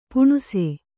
♪ puṇuse